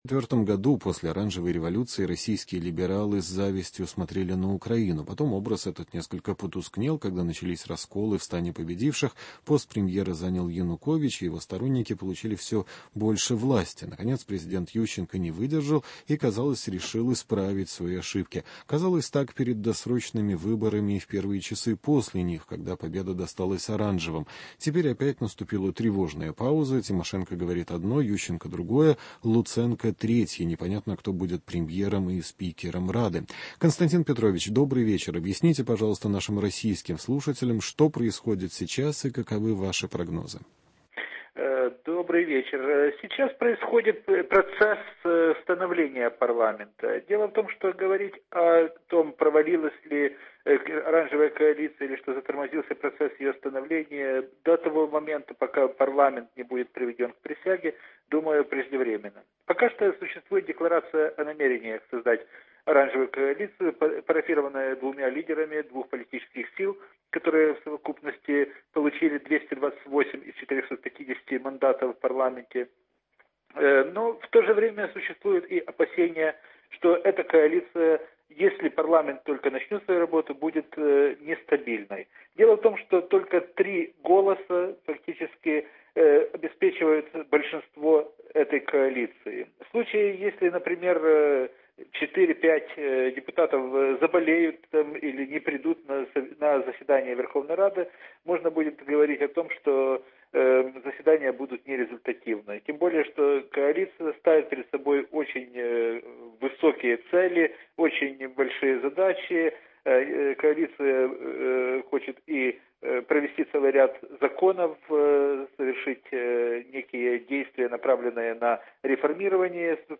Беседа с экспертами о последних событиях в России и на Украине, обзор американских еженедельников и российских Интернет-изданий, хроника неполитических событий, рубрики «Вопрос недели» и «Человек недели».